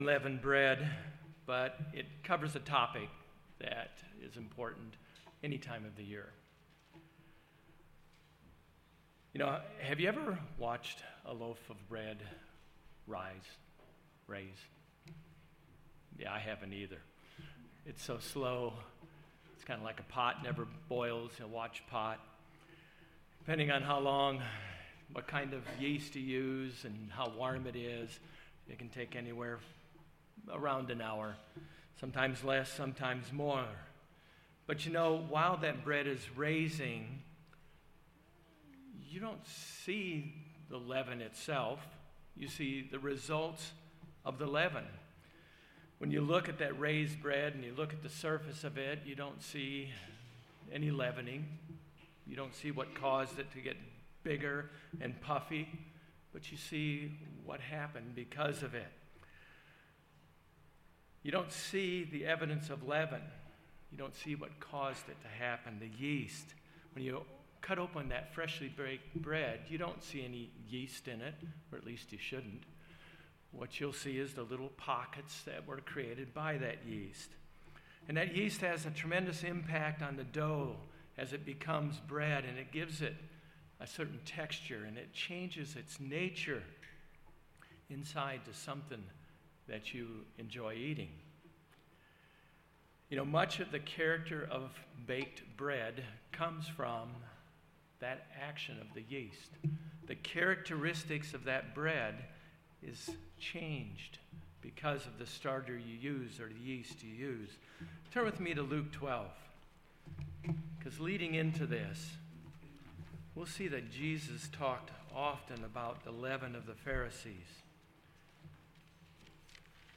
Jesus warned the disciples about the leaven of the Pharisees. In this sermon we review several characteristics of this leaven.
Given in Jacksonville, FL